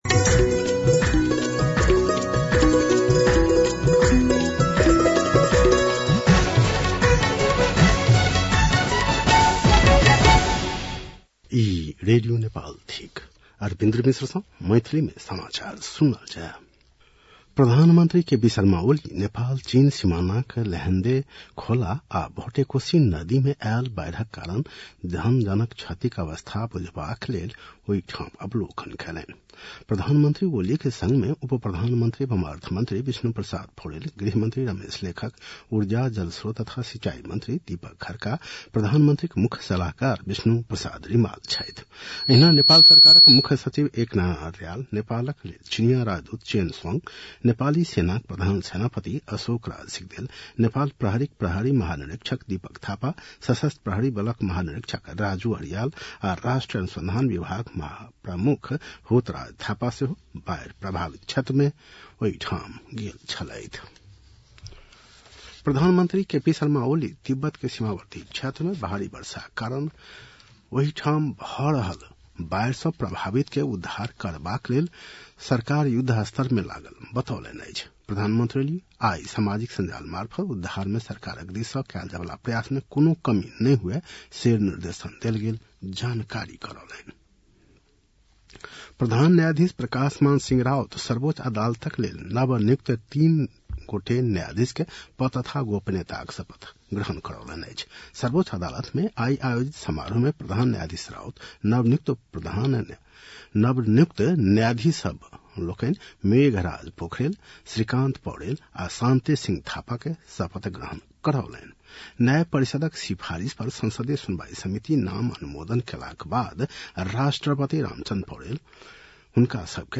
मैथिली भाषामा समाचार : २४ असार , २०८२